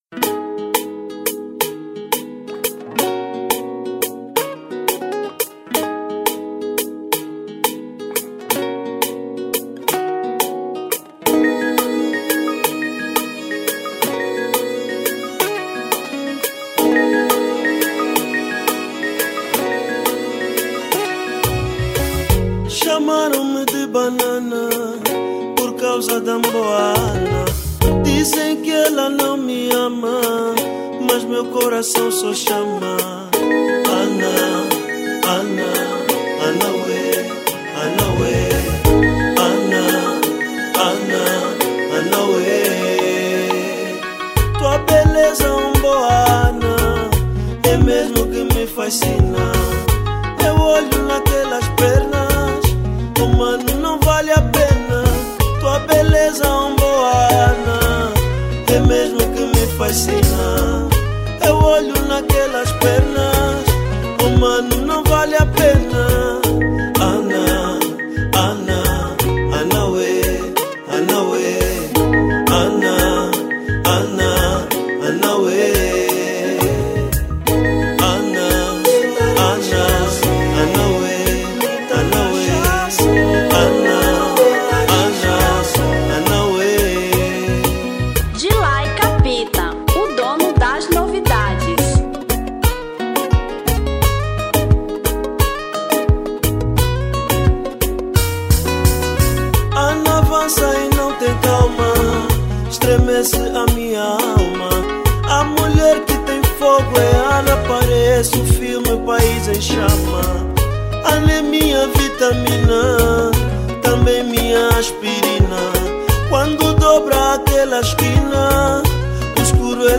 Kizomba 2005